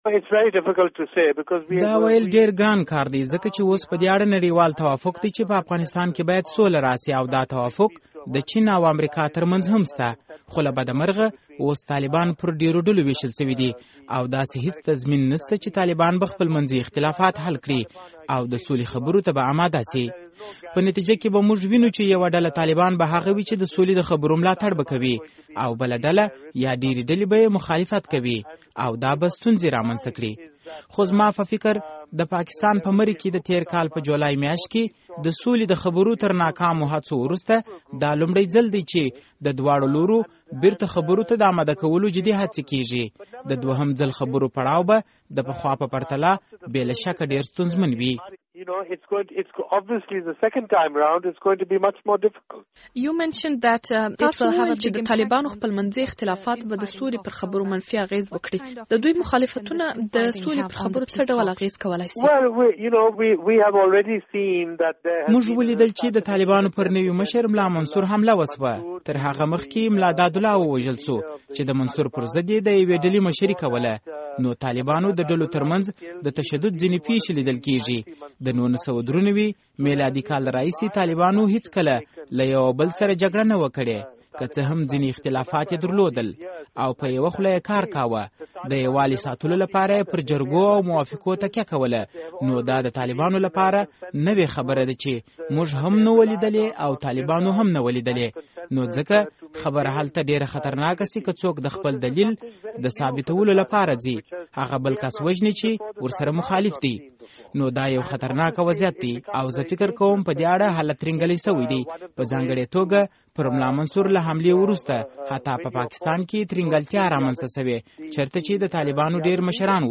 له کارپوه احمد رشید سره مرکه